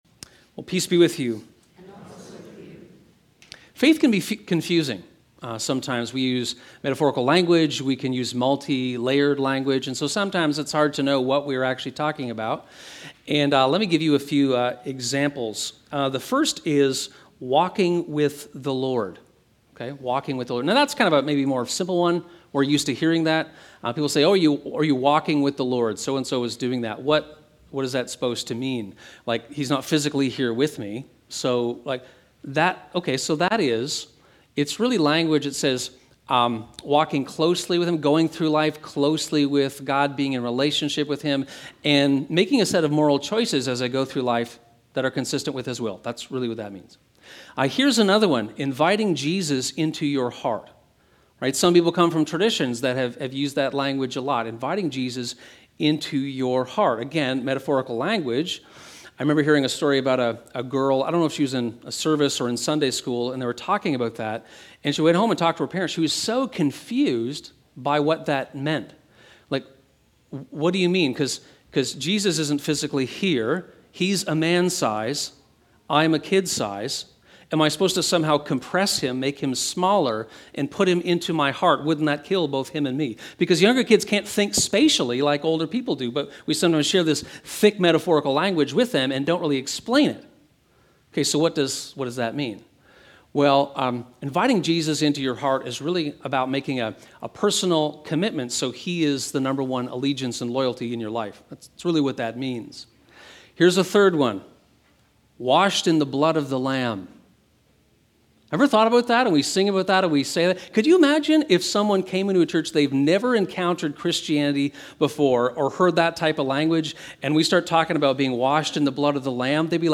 How does the peace of Christ rule in our hearts? (Sermon)
Sermon_Audio_May19.mp3